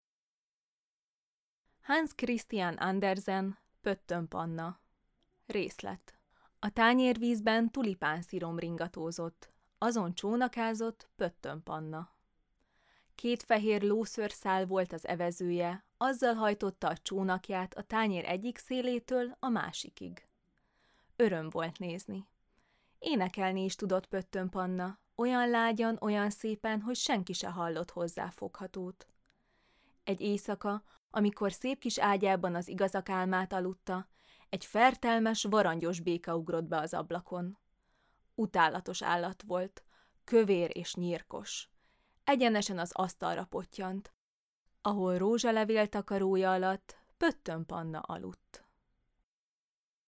AUDIONARRÁTOROK TÁRSASÁGA